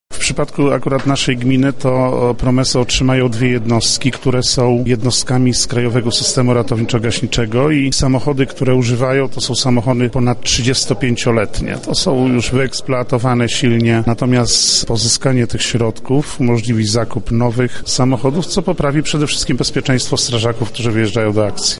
O tym, gdzie między innymi trafią pieniądze mówi Zbigniew Chlaściak, wójt gminy Stężyca: